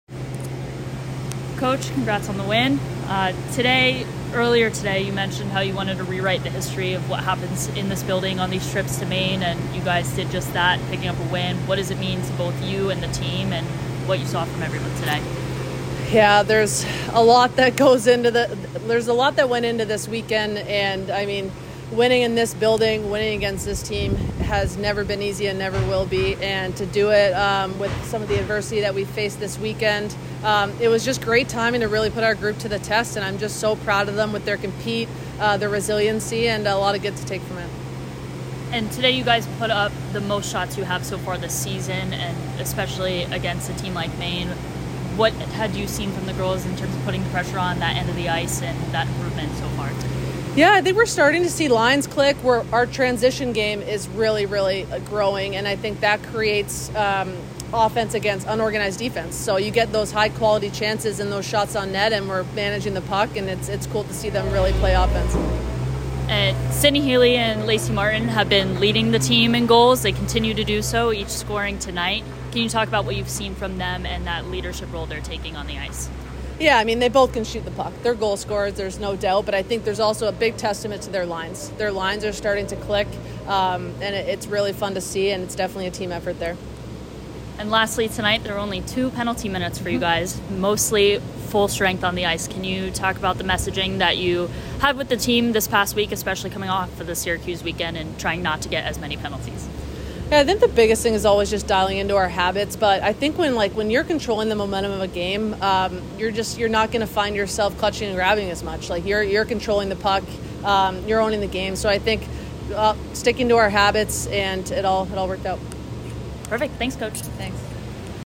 Women's Ice Hockey / Maine Postgame Interview (10-28-23)